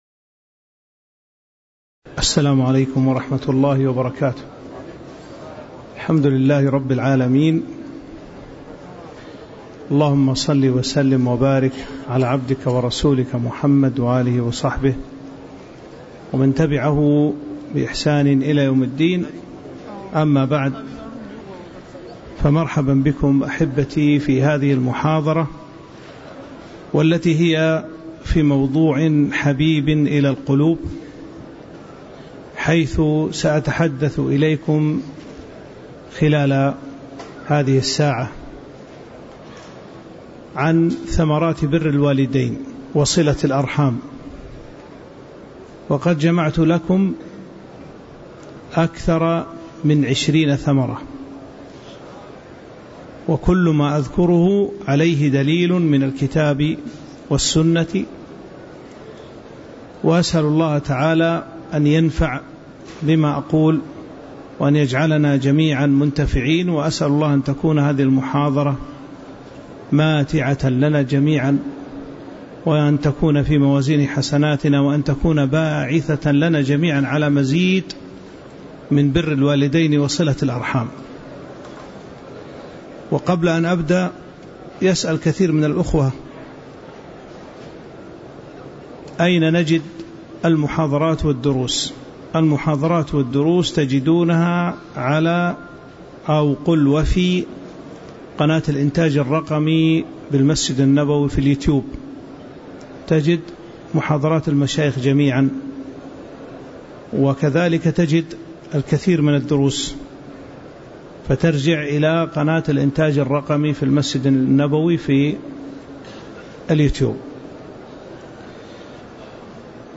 تاريخ النشر ٧ ربيع الأول ١٤٤٦ هـ المكان: المسجد النبوي الشيخ